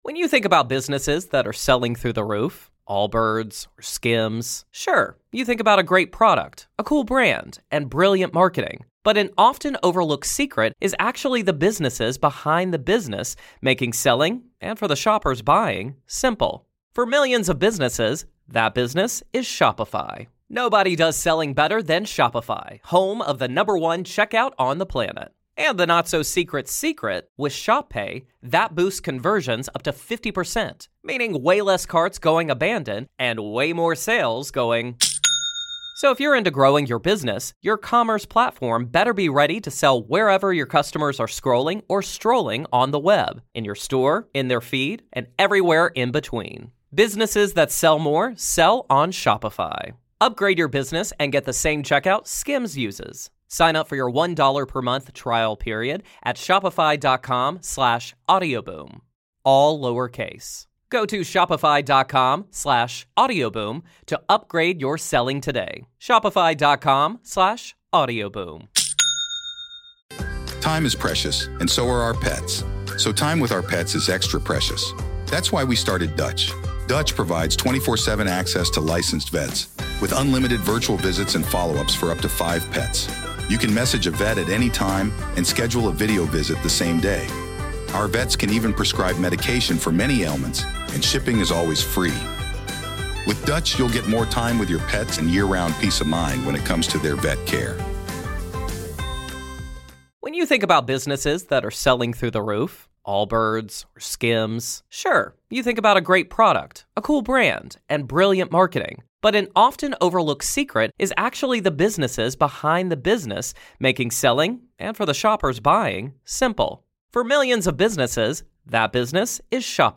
In this gripping conversation, we dive deep into the mystery of Ghost Box communication, exploring theories, shocking encounters, and the terrifying implications of who—or what—might be answering back.